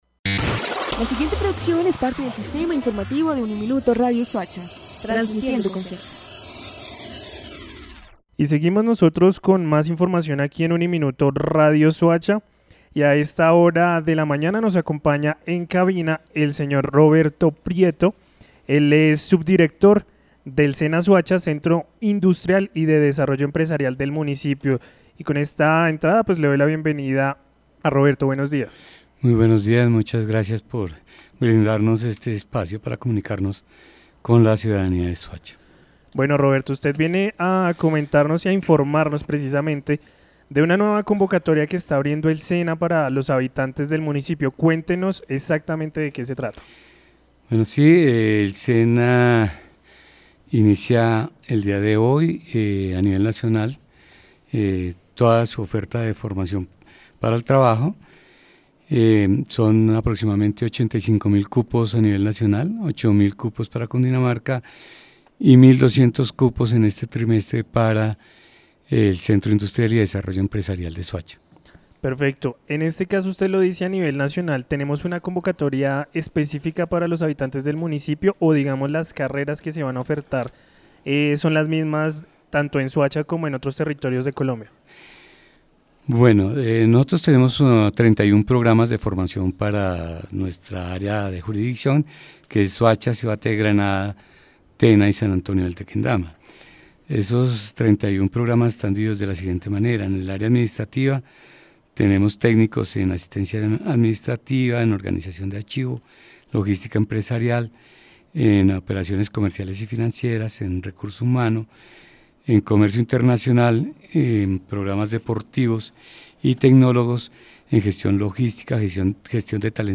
habló en los micrófonos de UNIMINUTO Radio Soacha